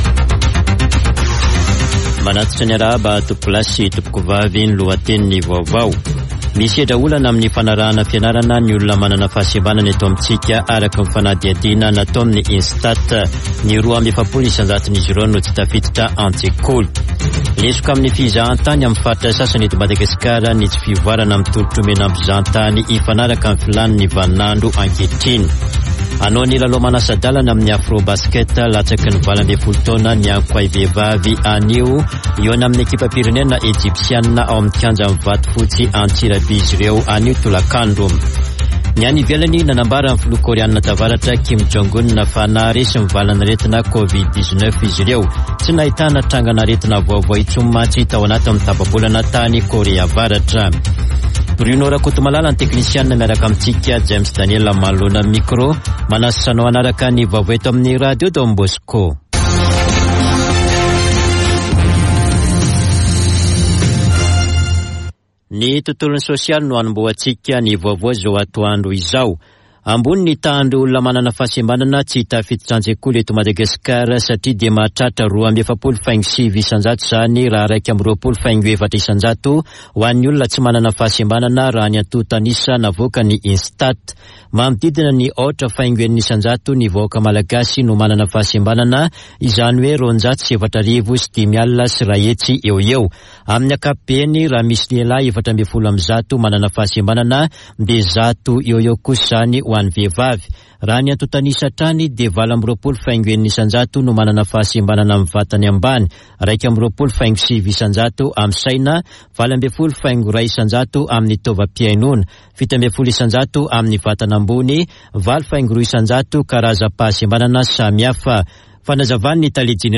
[Vaovao antoandro] Alakamisy 11 aogositra 2022